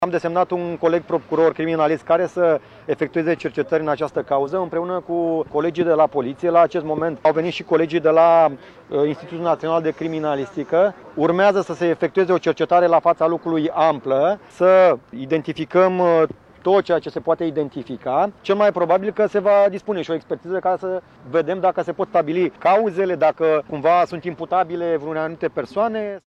01oct-14-Procuror-despre-ancheta.mp3